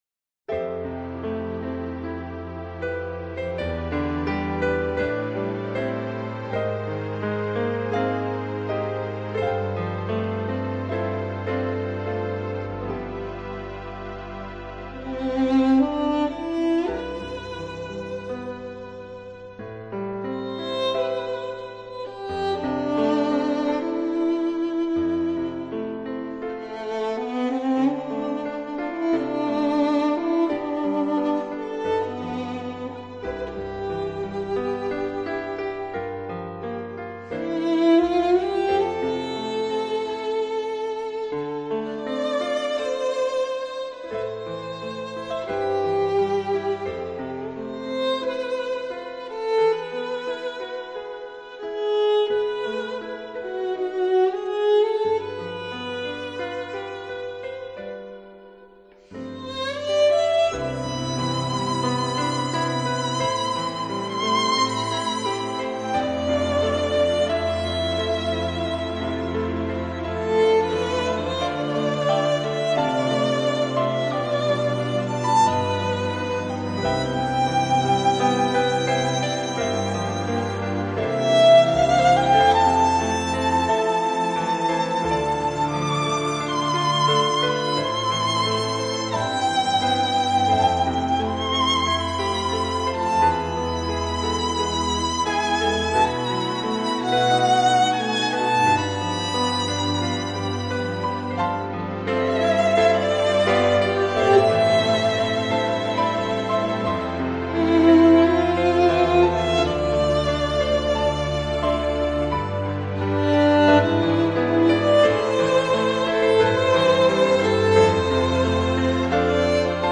Жанр: Keyboards, Harp, Violin, Instrumental